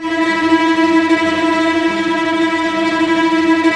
Index of /90_sSampleCDs/Roland L-CD702/VOL-1/STR_Vcs Tremolo/STR_Vcs Trem f